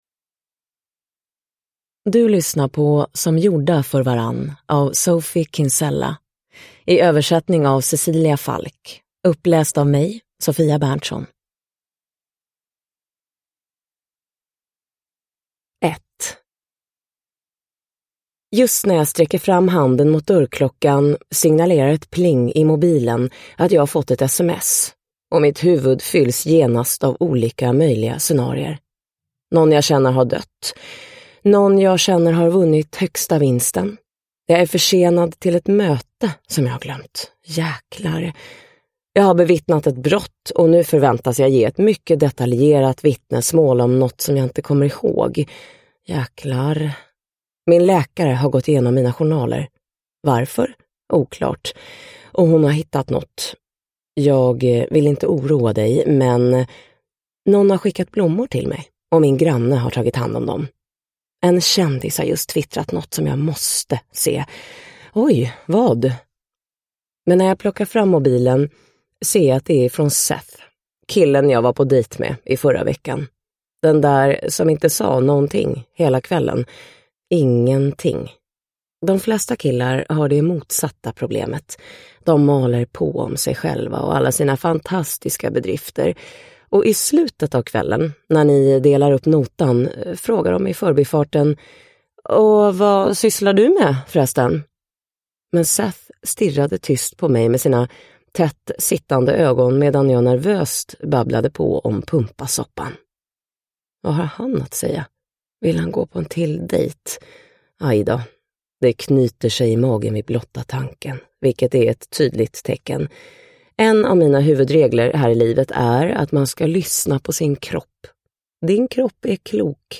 Som gjorda för varann – Ljudbok – Laddas ner
Uppläsare: Anja Lundqvist